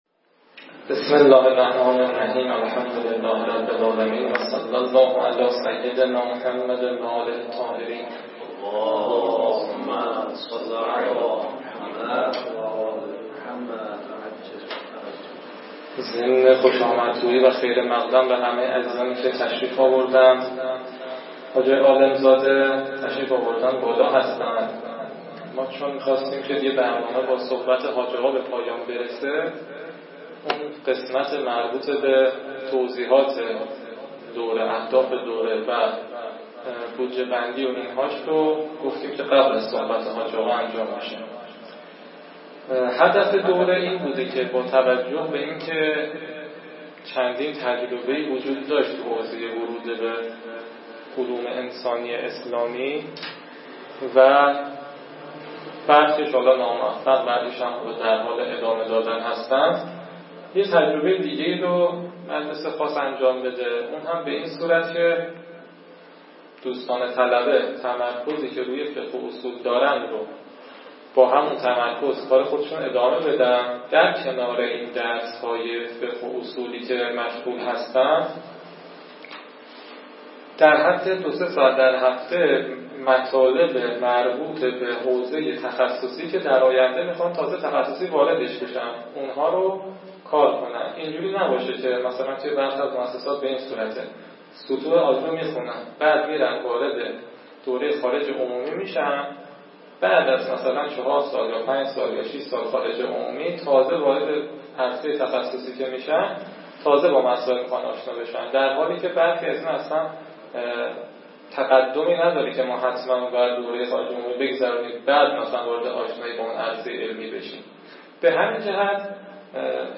نشست علمی